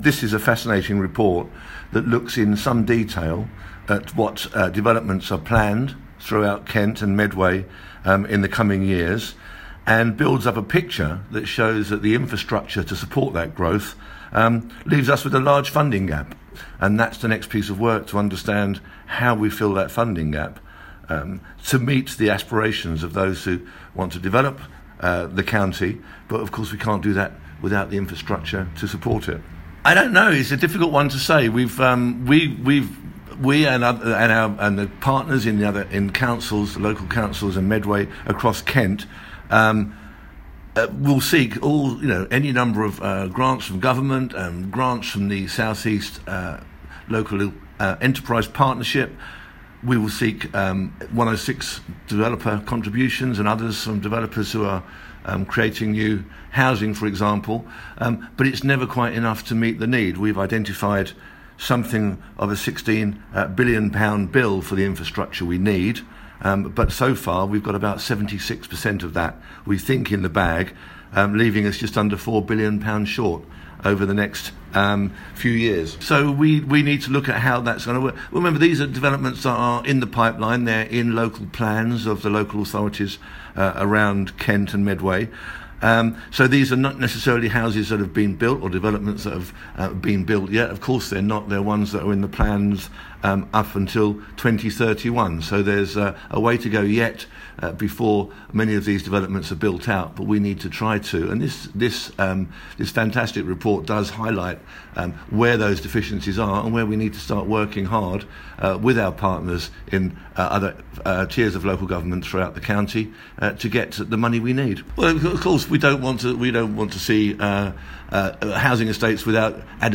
LISTEN: Cabinet member for transport Cllr Mike Whiting says it's KCC's job to ensure they can meet the demand in population growth - 28/06/18
But has revealed a funding gap of £3.6 billion, which the county council say they can't explain. Cllr Mike Whiting is the cabinet member for highways and transport.